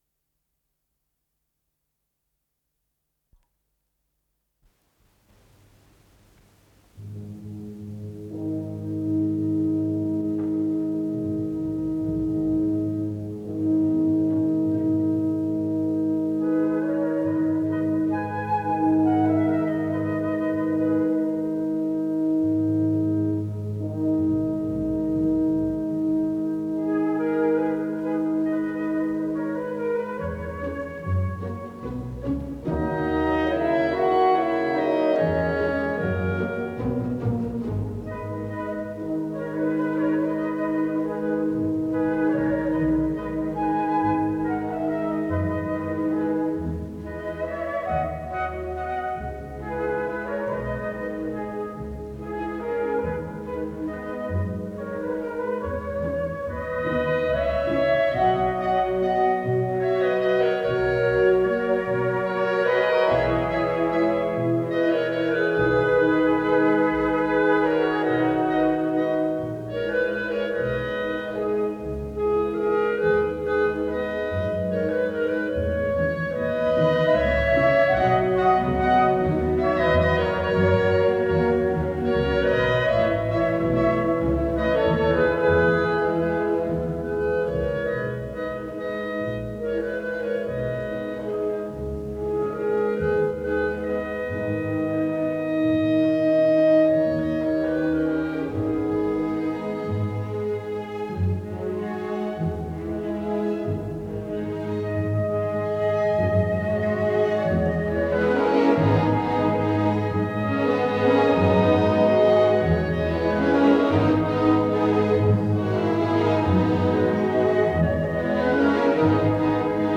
с профессиональной магнитной ленты
Андатино кон мото, квази алегретто
ИсполнителиСимфонический оркестр Финского Радио
Дирижёр - Окко Каму
ВариантДубль моно